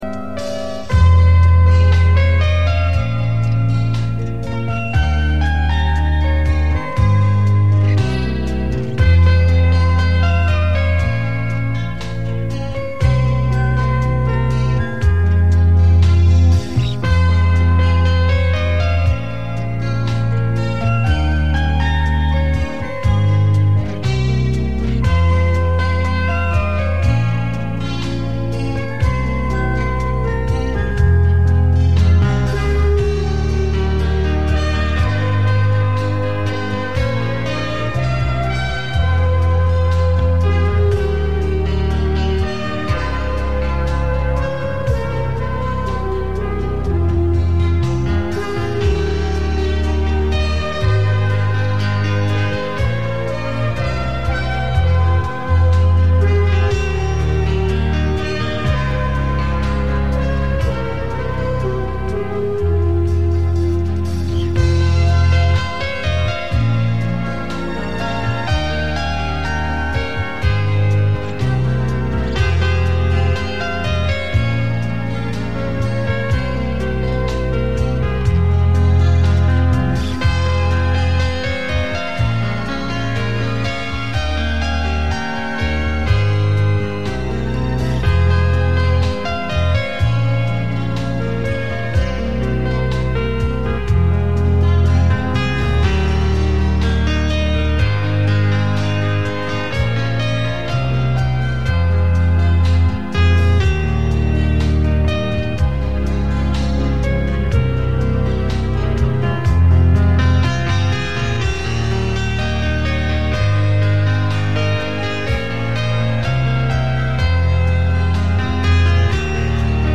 НА ОПОЗНАНИЕ ИНСТРУМЕНТАЛ